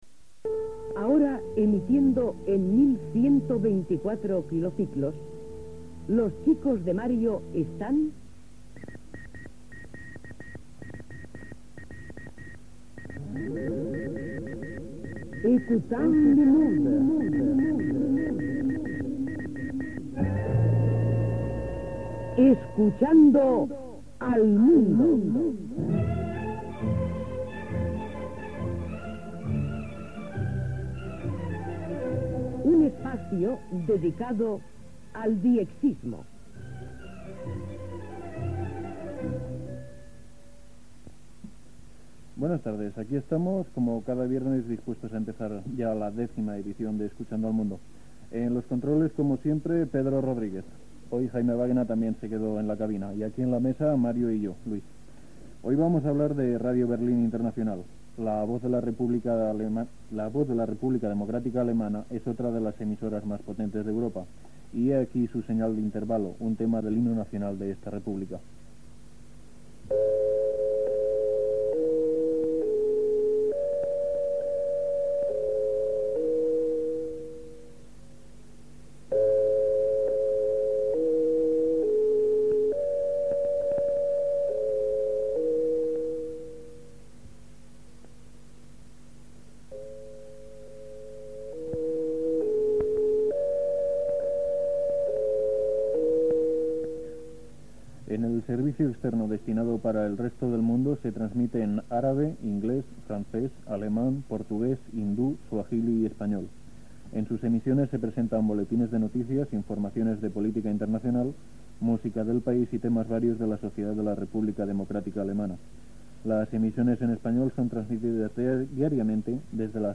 Gènere radiofònic Divulgació